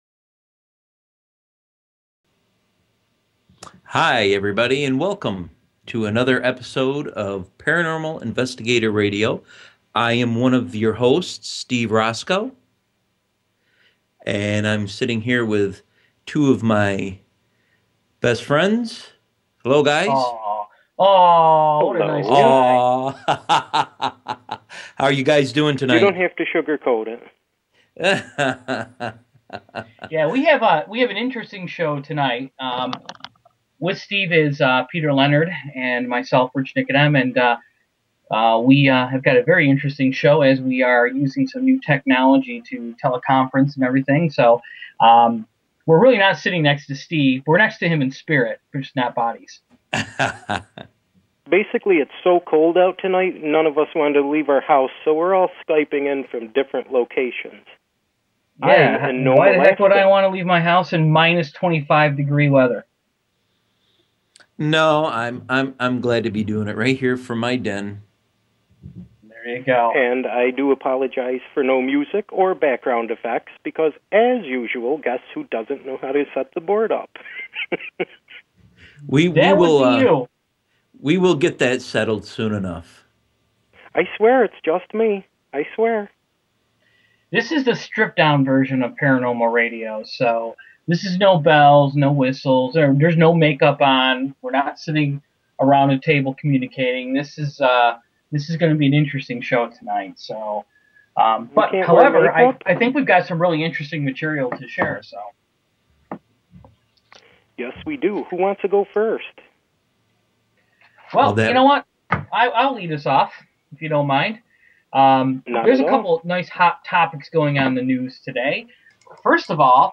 Talk Show